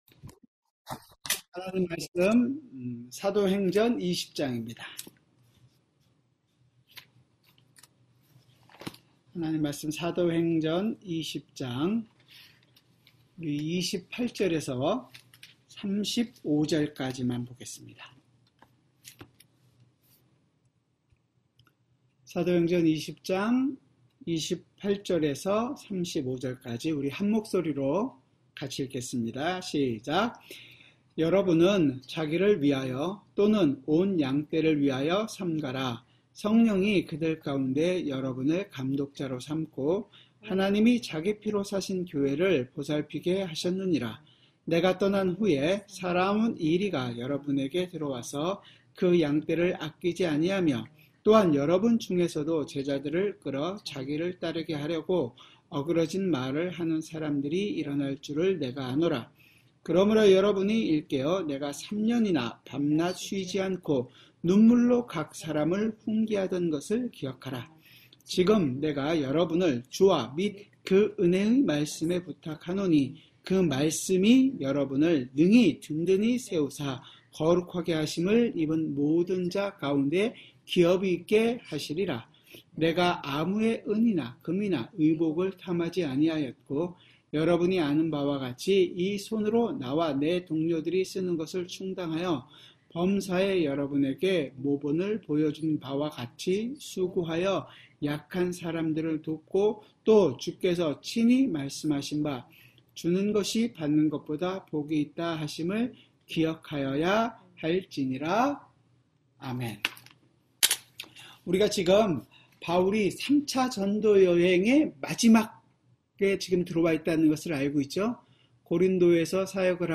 수요성경공부